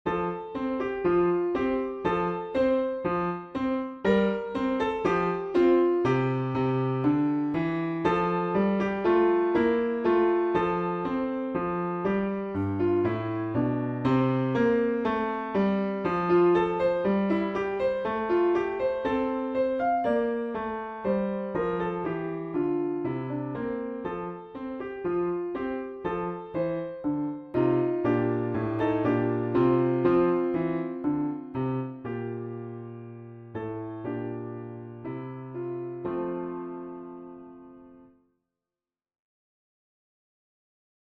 grade 2 piano level piece